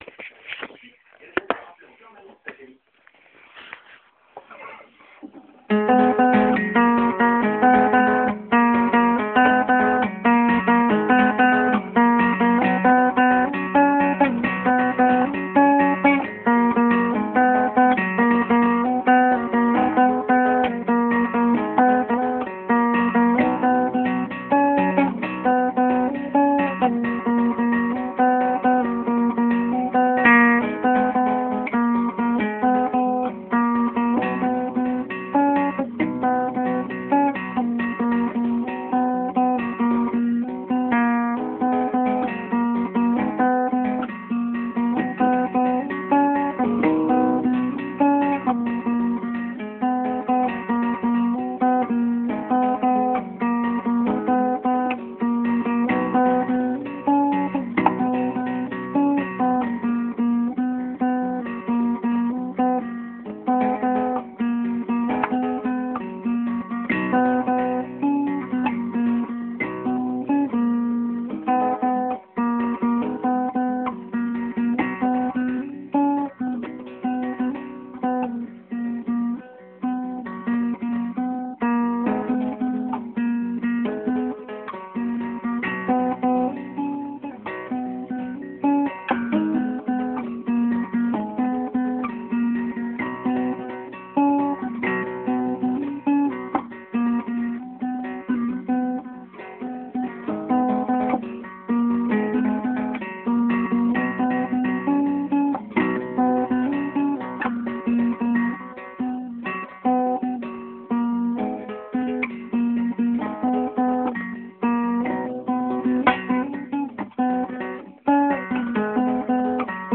Compo guitare
Ma composition 'Obstinément', chant + guitare :